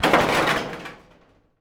metal_sheet_impacts_02.wav